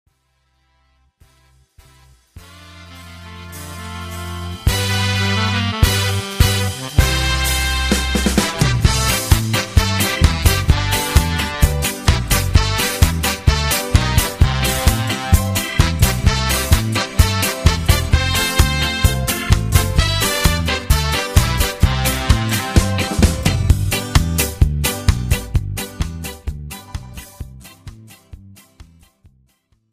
With Backing Vocals. Professional Karaoke Backing tracks.
Category Pop